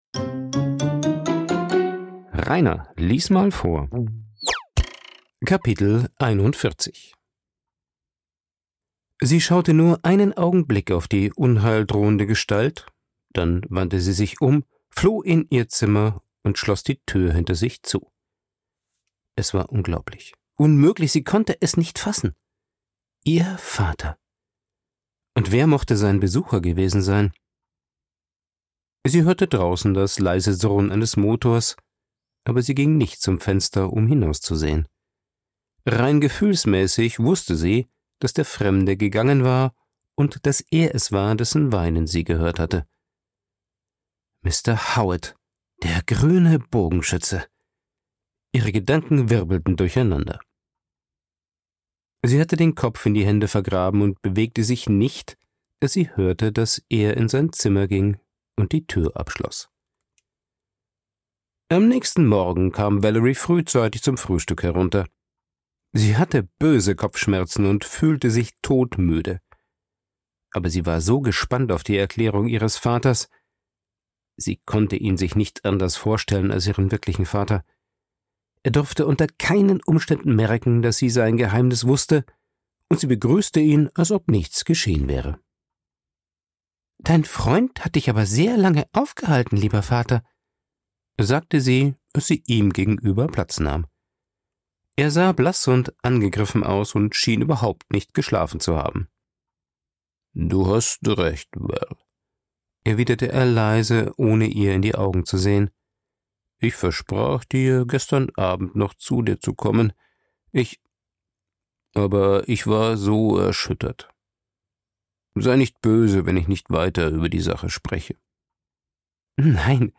Ein Vorlese Podcast